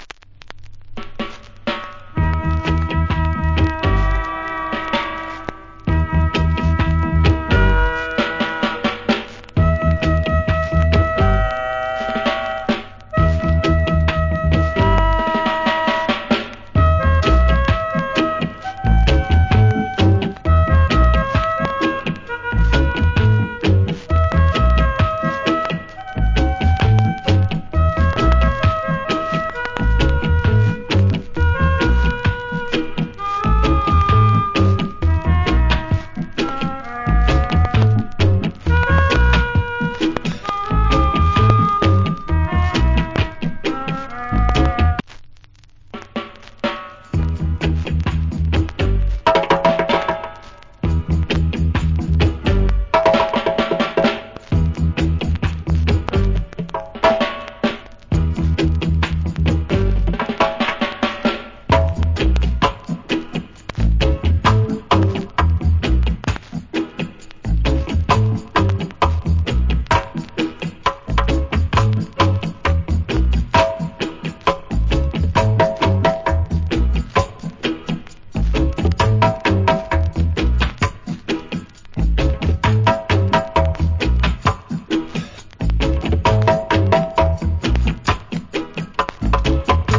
Wicked Reggae Inst.